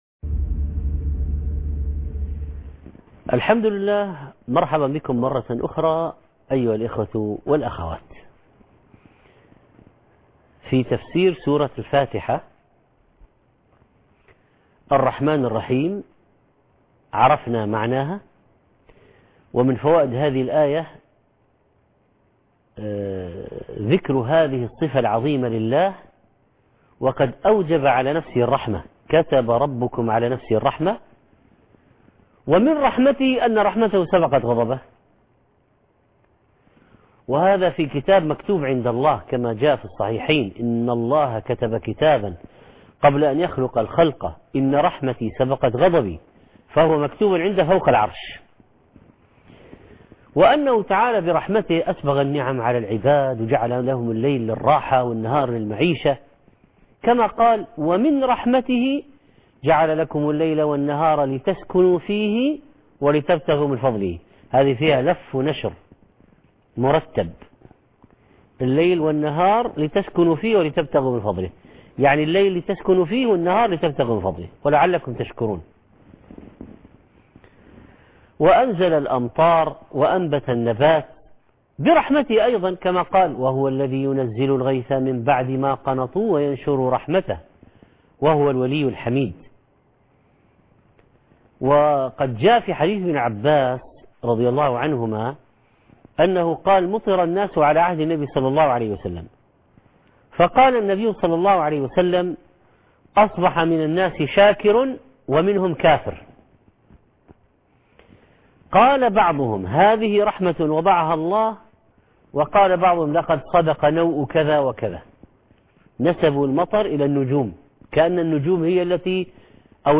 المحاضرة الحادية عشر - تفسير سورة الفاتحة - تتمة الرحمن الرحيم ( 17/3/2016 ) التفسير - الشيخ محمد صالح المنجد